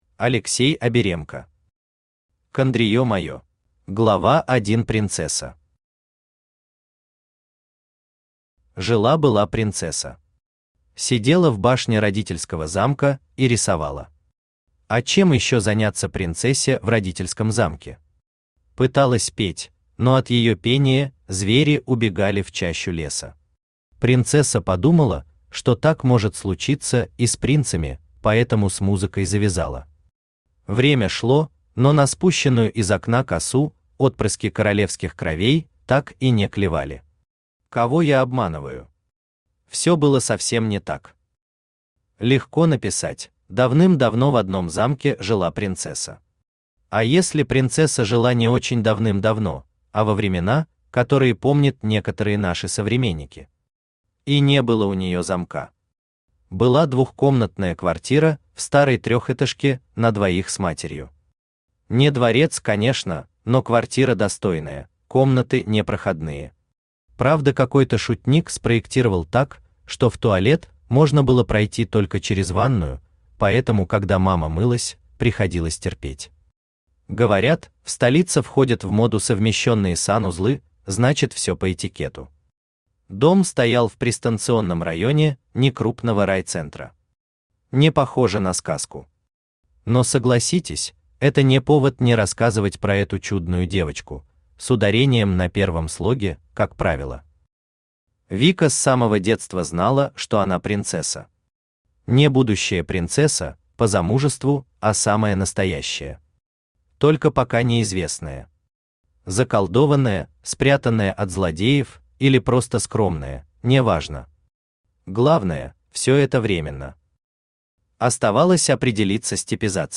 Аудиокнига Кондриё моё | Библиотека аудиокниг
Aудиокнига Кондриё моё Автор Алексей Евгеньевич Аберемко Читает аудиокнигу Авточтец ЛитРес.